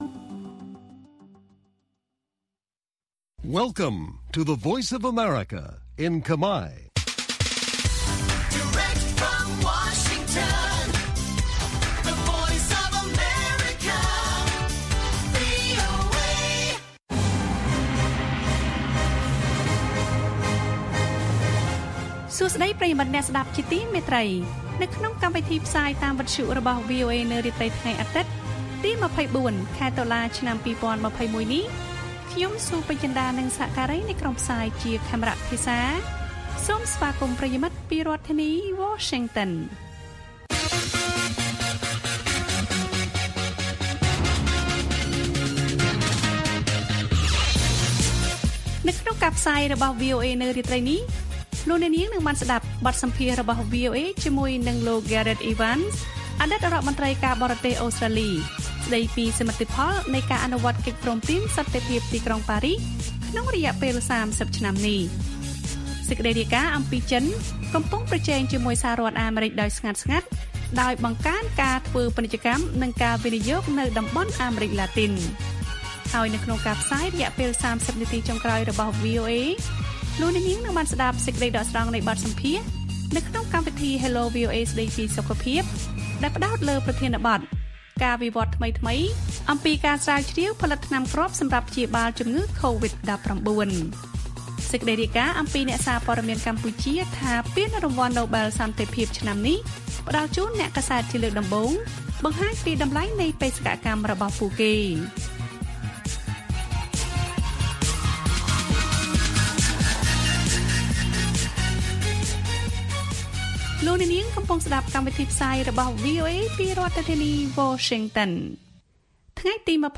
ព័ត៌មានអំពីកម្ពុជាមាន បទសម្ភាសន៍VOA៖ អតីតរដ្ឋមន្ត្រីការបរទេសអូស្ត្រាលីលោក Gareth Evans ទទូចពលរដ្ឋកម្ពុជាឲ្យរក្សាសុទិដ្ឋិនិយម។ បទសម្ភាសន៍ដកស្រង់ពីកម្មវិធី Hello VOA៖ ការវិវត្តថ្មីៗអំពីថ្នាំគ្រាប់សម្រាប់ព្យាបាលជំងឺកូវីដ១៩។ អ្នកសារព័ត៌មានកម្ពុជាថា ពានរង្វាន់ណូបែលសន្តិភាពឆ្នាំនេះផ្តល់ជូនអ្នកកាសែតជាលើកដំបូង បង្ហាញពីតម្លៃនៃបេសសកម្មរបស់ពួកគេ។